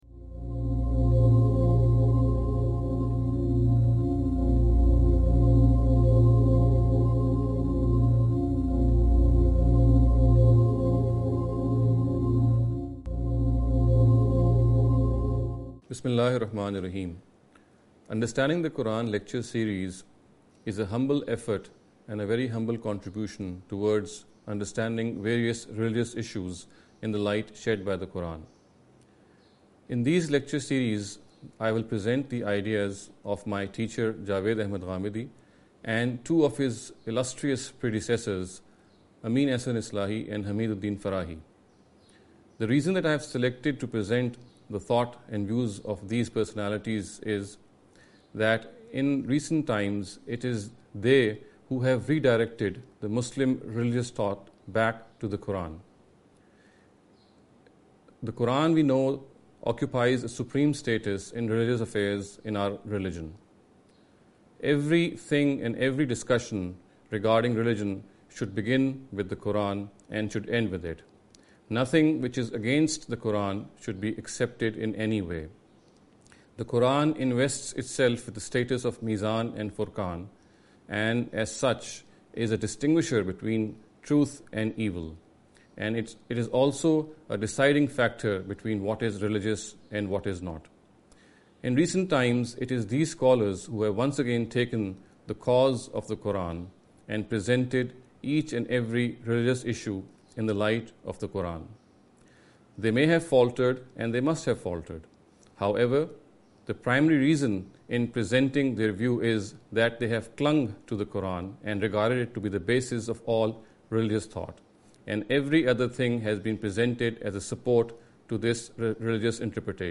This lecture series will deal with some misconception regarding the Understanding The Qur’an. In every lecture he will be dealing with a question in a short and very concise manner.